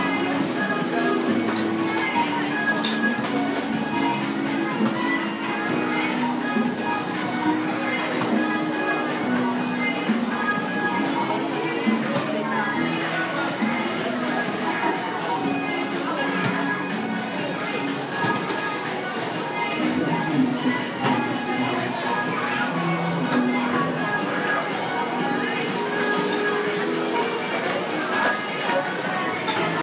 The music is loud. Noise from several sources make a dense and dirty carpet.